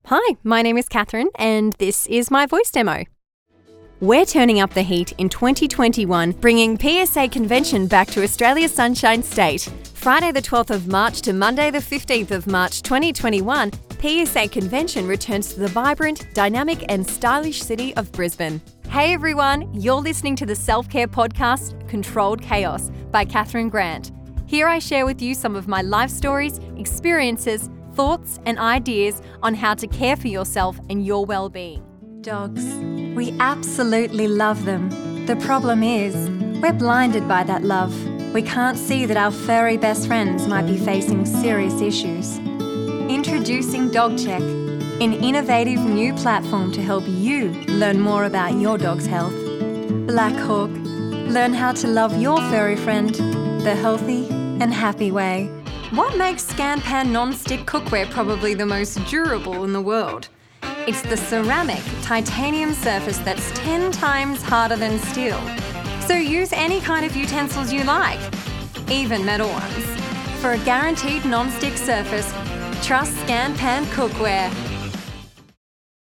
Female
English (Australian)
Yng Adult (18-29), Adult (30-50)
Noted for my bright, engaging & warm personas; I can record and have experience in a wide range of voiceover projects from commercials to audiobooks.
Highlight Voicereel
Words that describe my voice are Bright, Engaging, Genuine.